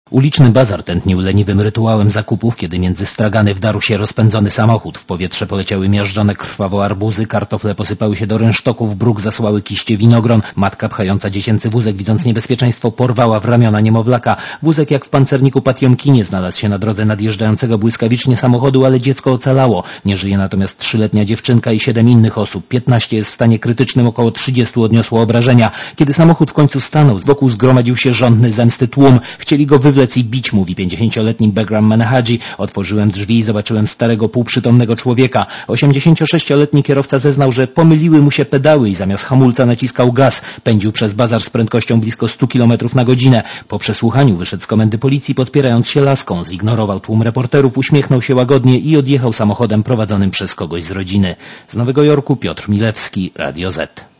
Posłuchaj relacji korespondenta (212Kb)